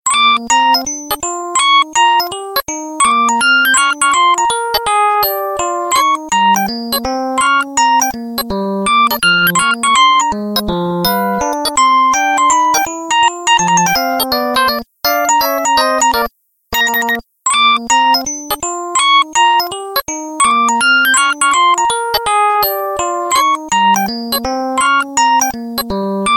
The Whistle